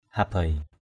/ha-beɪ/